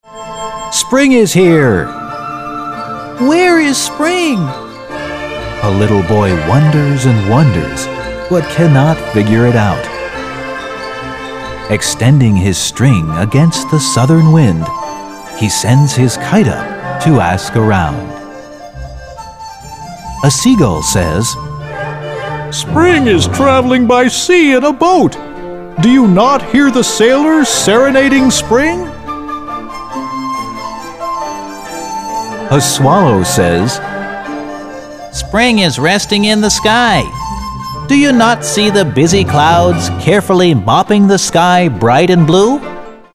【朗讀版】